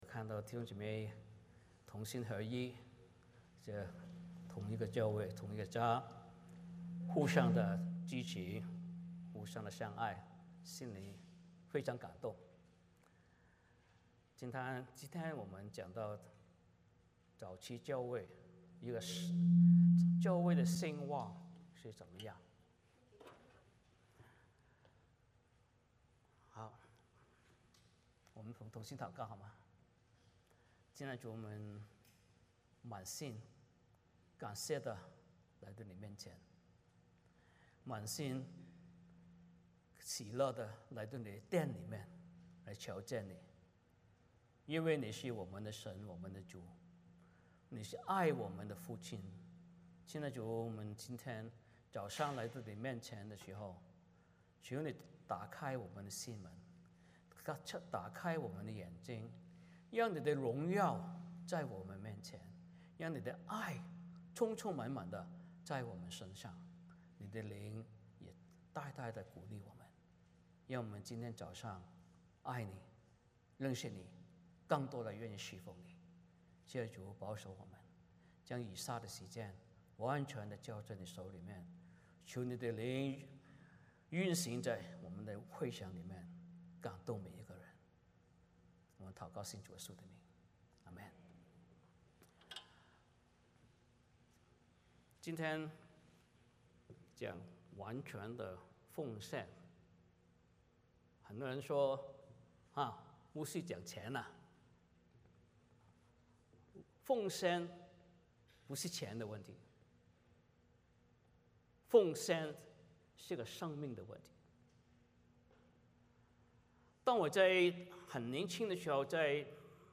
欢迎大家加入我们国语主日崇拜。
11 Service Type: 主日崇拜 欢迎大家加入我们国语主日崇拜。